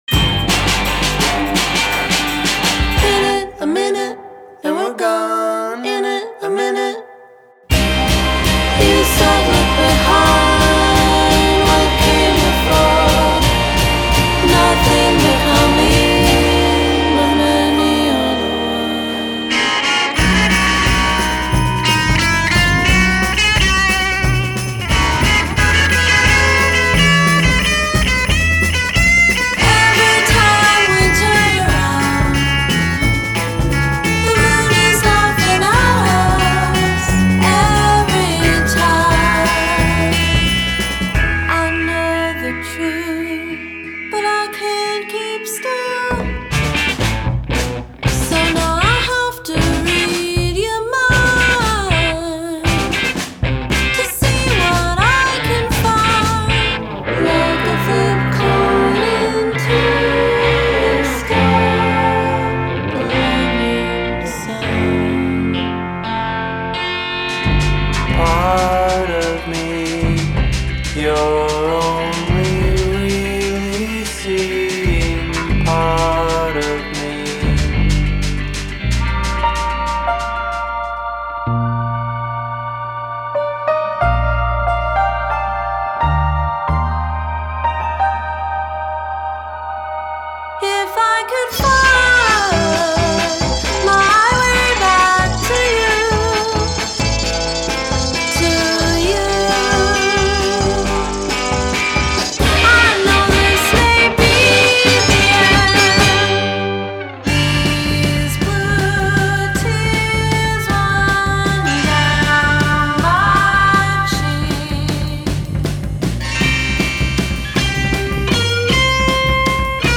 percussionist